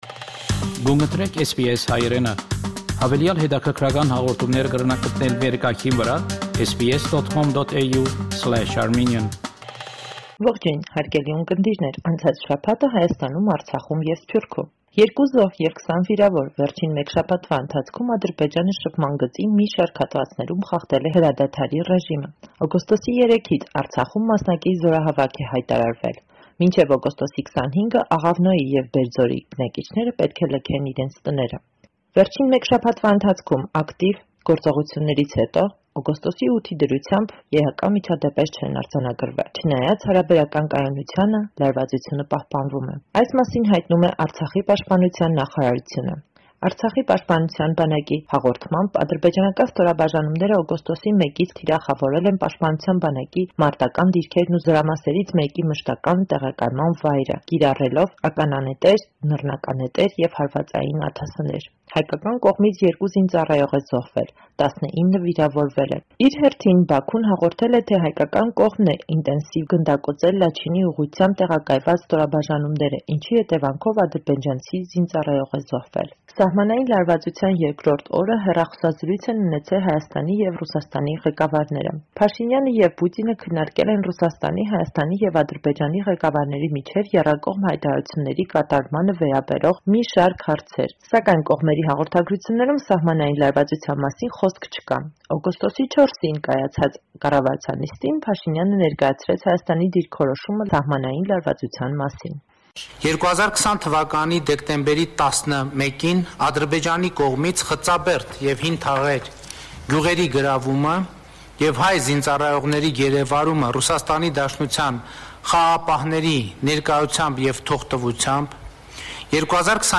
Latest News from Armenia – 9 August 2022